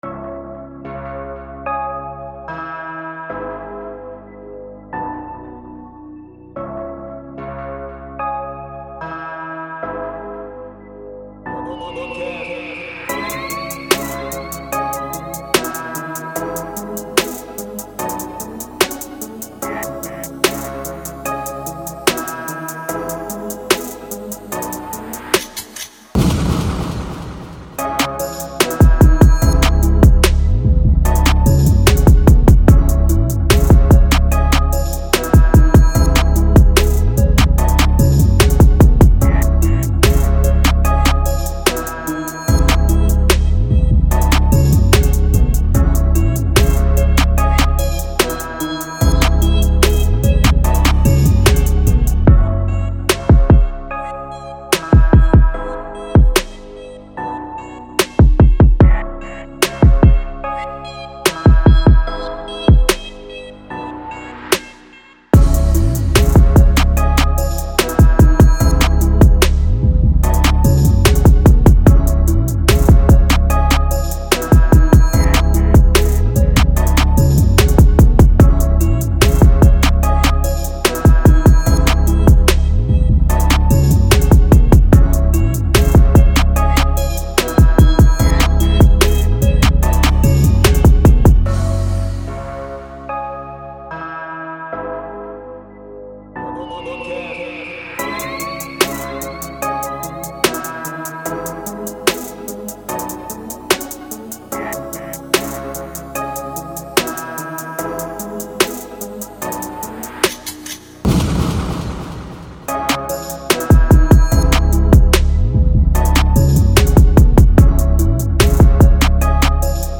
Un VST aux sonorités sombres/dark